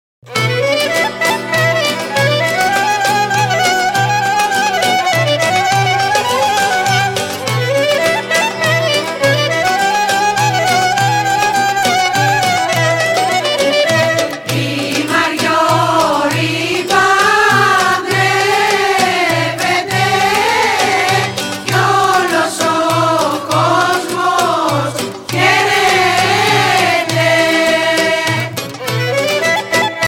Σύλλογος παραδοσιακών χορών-χορωδίας